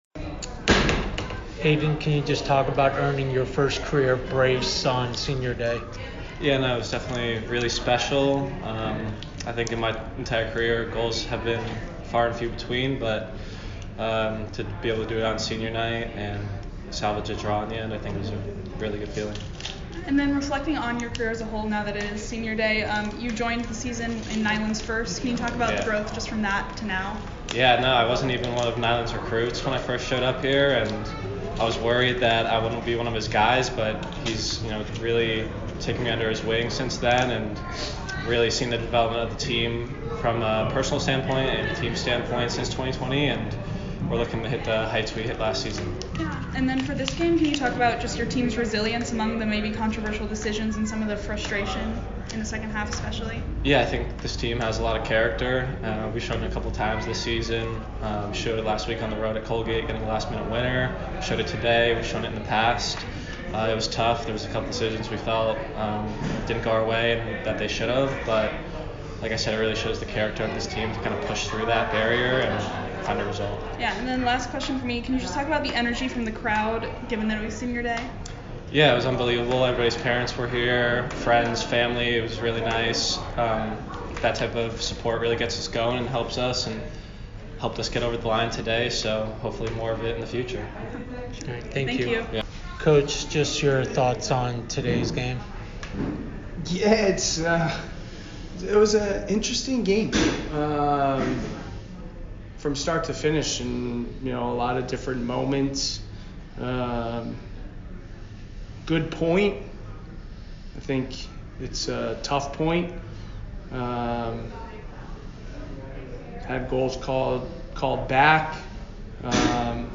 American Postgame Interview